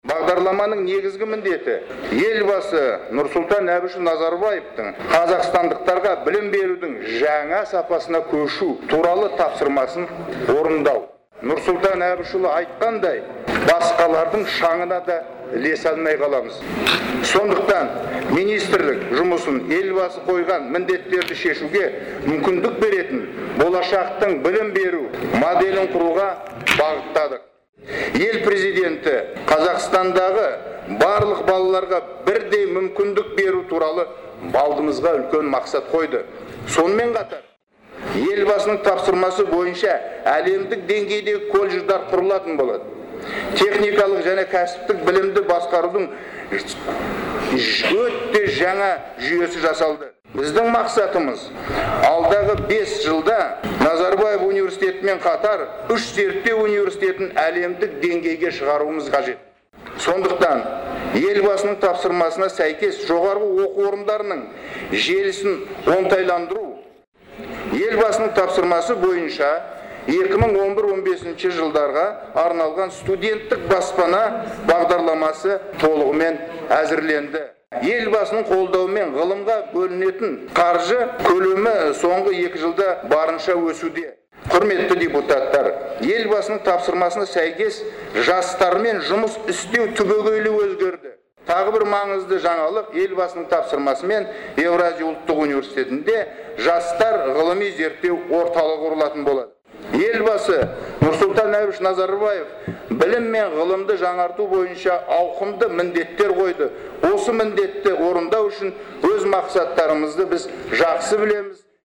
Мәжілісте өткен үкімет сағатында білім және ғылым министрі Бақытжан Жұмағұлов әр сөйлемін президенттің есімімен нығырлап сөйледі.